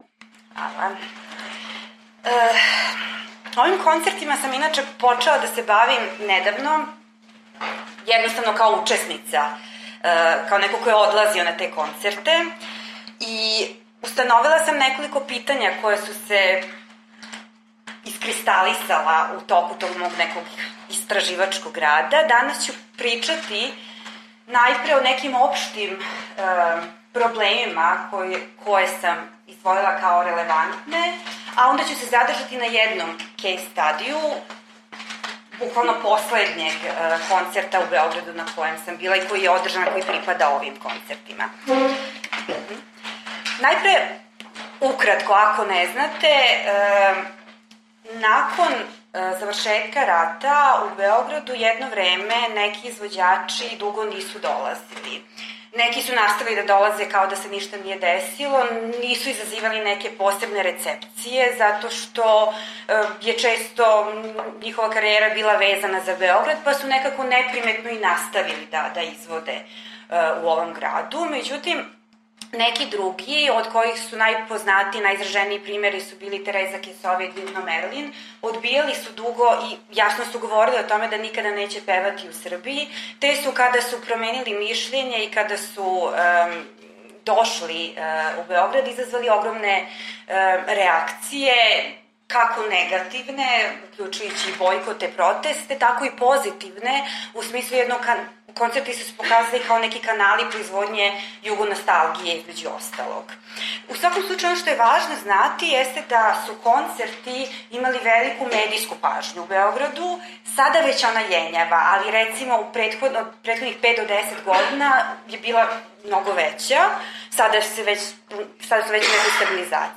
28. in 29. novembra 2014 se je na Oddelku za etnologijo in kulturno antropologijo Filozofske fakultete v Ljubljani odvijal znanstveni posvet Odmevnost jugoslovanske glasbe in sodobne popularne glasbe z območja nekdanje Jugoslavije.